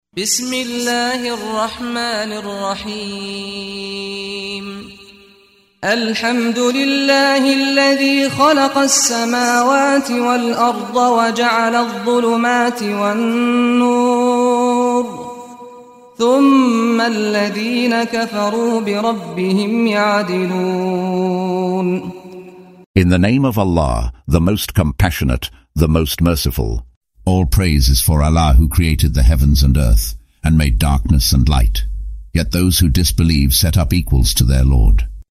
Audio version of Surah Al-An'am ( The Cattle ) in English, split into verses, preceded by the recitation of the reciter: Saad Al-Ghamdi.